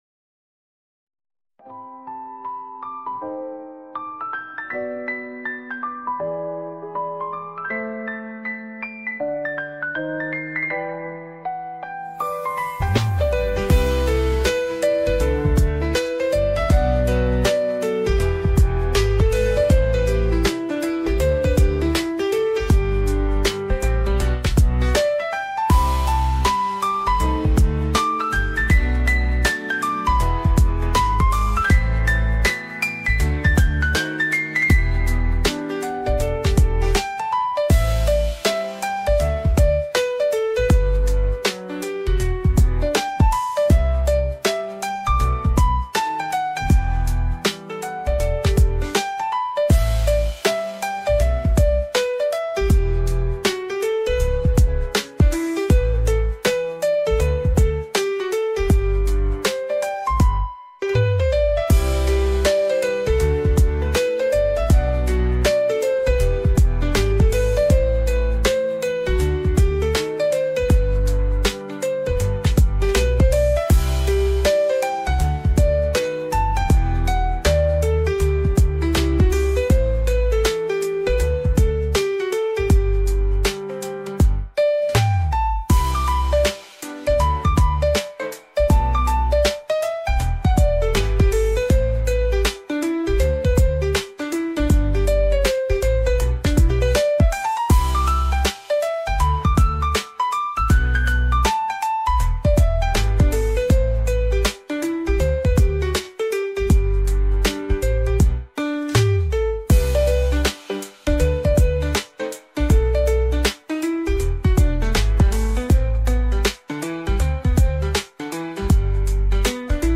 lo-fi music